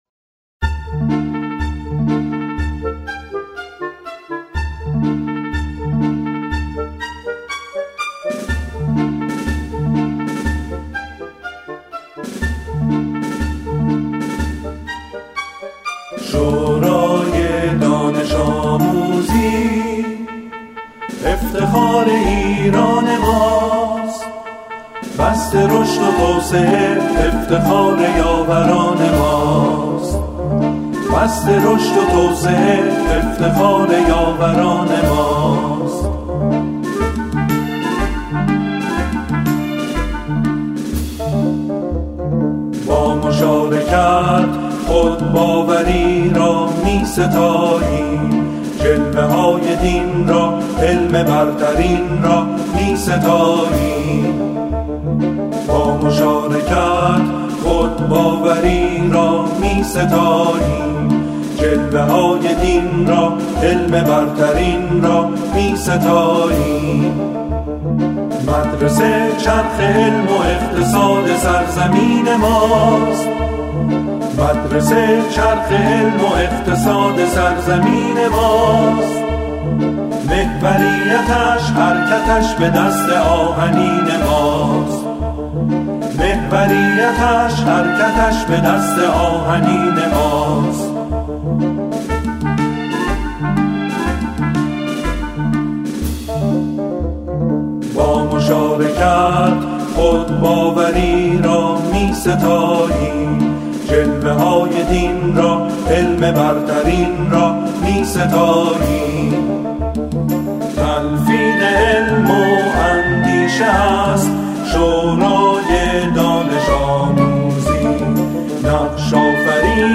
همخوانی
با صدای اعضای گروه کر